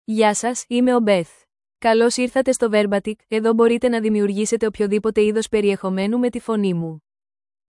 BethFemale Greek AI voice
Voice sample
Listen to Beth's female Greek voice.
Female
Beth delivers clear pronunciation with authentic Greece Greek intonation, making your content sound professionally produced.